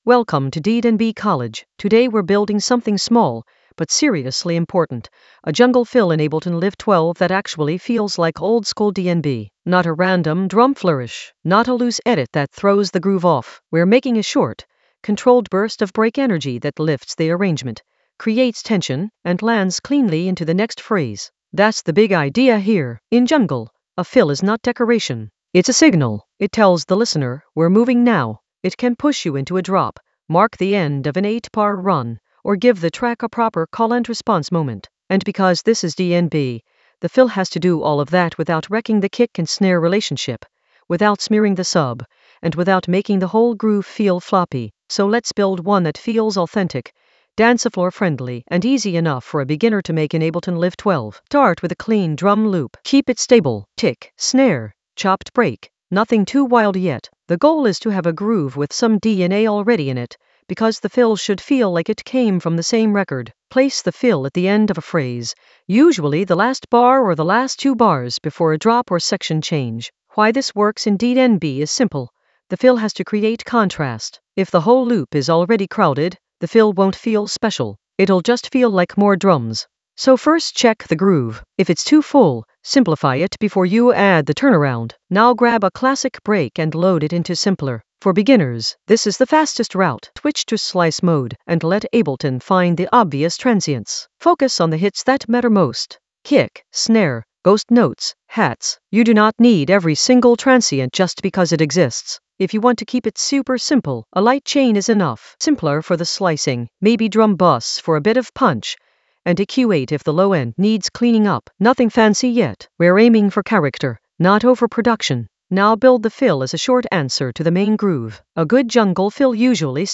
An AI-generated beginner Ableton lesson focused on Sequence a jungle fill in Ableton Live 12 for jungle oldskool DnB vibes in the Automation area of drum and bass production.
Narrated lesson audio
The voice track includes the tutorial plus extra teacher commentary.